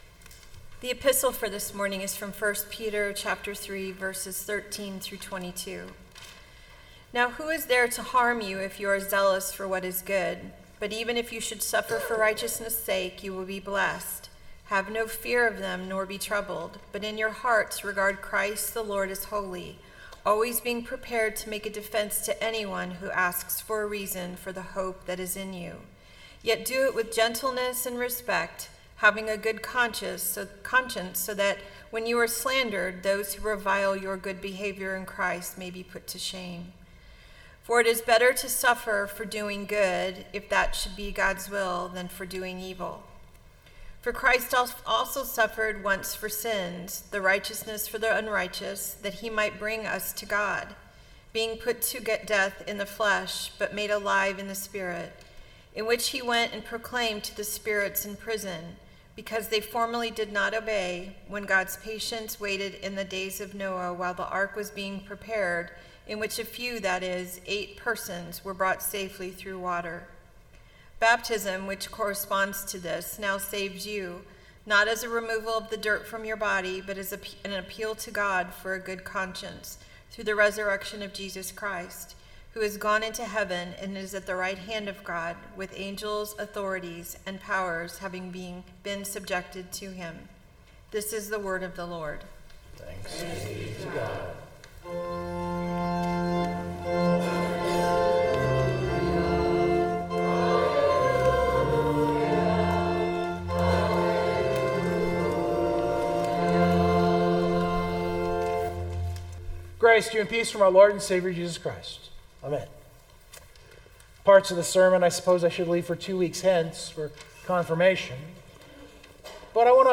Full Sermon Draft Download Biblical Texts: Acts 17:16-31, 1 Peter 3:13-22, John 14:15-21 I gave myself a bit more freedom today.